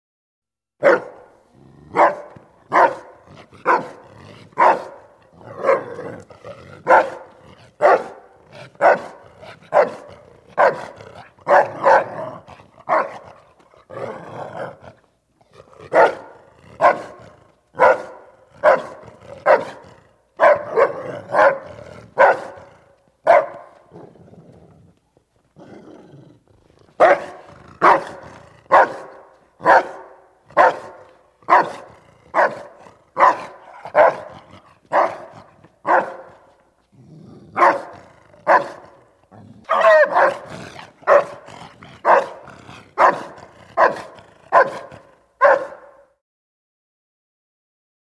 DogDobermanBarksLo AT020701
Dog, Doberman Barks. Low Pitched, Reverberant, Snarl-like Barks With Light Pants. Close Perspective.